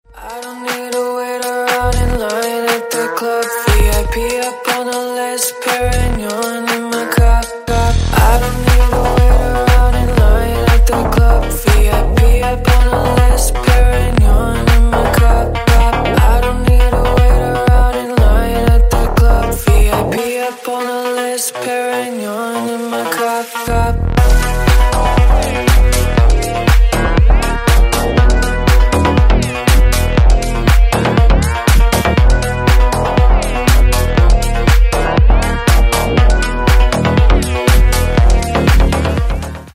Клубные Рингтоны
Рингтоны Электроника